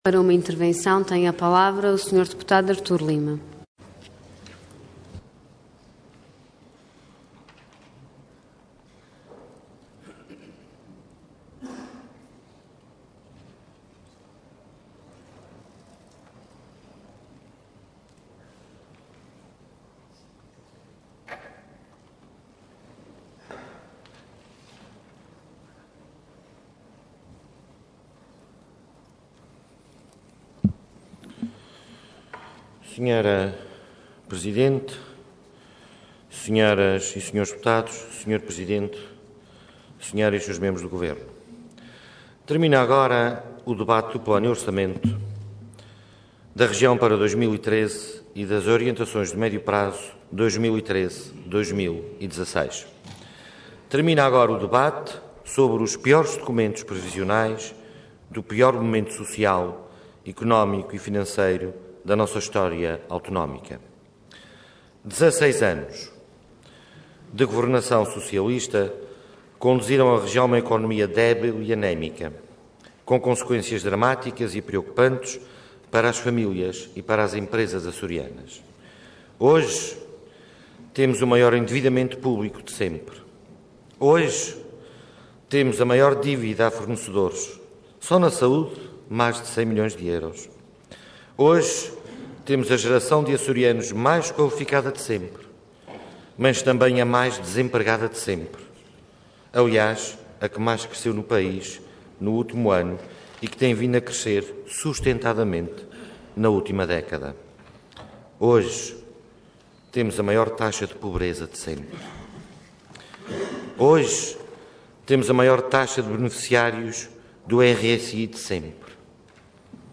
Detalhe de vídeo 21 de março de 2013 Download áudio Download vídeo Diário da Sessão Processo X Legislatura no encerramento do debate das Propostas de Decreto Legislativo Regional, Plano e Orçamento para 2013 e Orientações de Médio Prazo 2013/2016. Intervenção Intervenção de Tribuna Orador Artur Lima Cargo Deputado Entidade CDS-PP